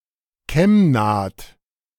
Kemnath (German: [ˈkɛm.naːt]
De-Kemnath.ogg.mp3